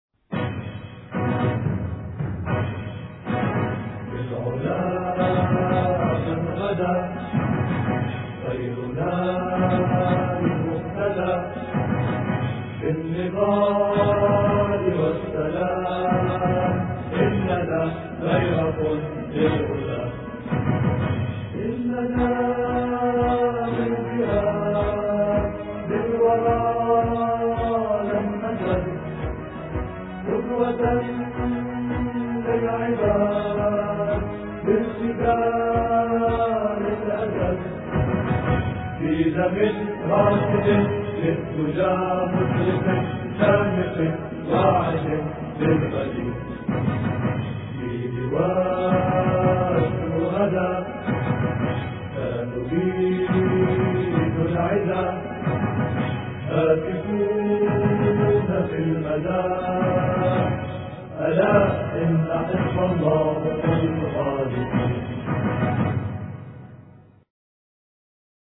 سلاحي لدحر العدى الإثنين 21 إبريل 2008 - 00:00 بتوقيت طهران تنزيل الحماسية شاركوا هذا الخبر مع أصدقائكم ذات صلة الاقصى شد الرحلة أيها السائل عني من أنا..